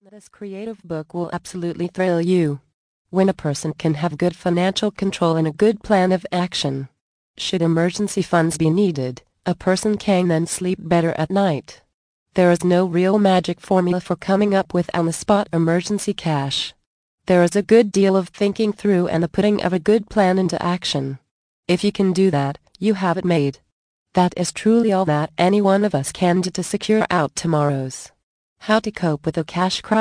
Emergency Quick Cash mp3 audio book part 1 of 4 + FREE GIFT